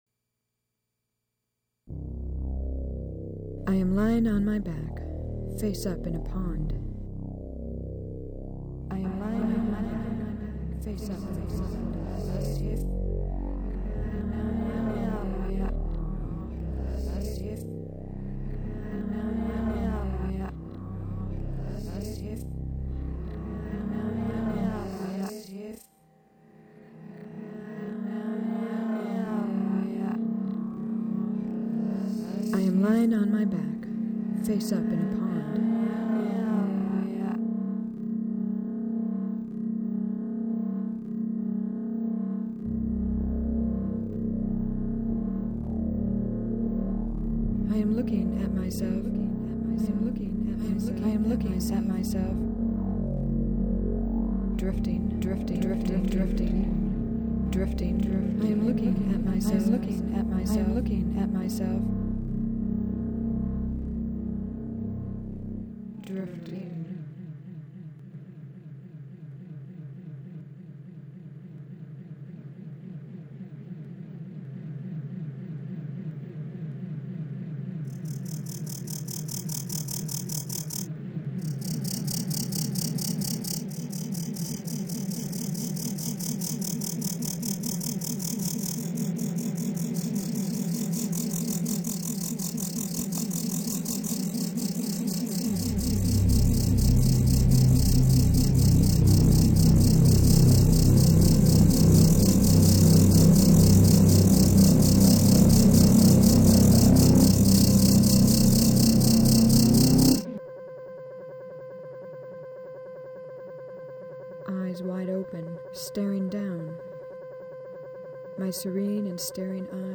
I make electronic music.
Eyes Wide Open - from my recital in April 2000